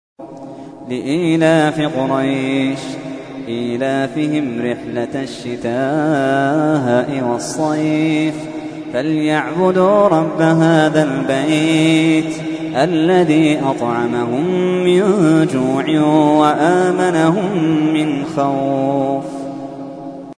تحميل : 106. سورة قريش / القارئ محمد اللحيدان / القرآن الكريم / موقع يا حسين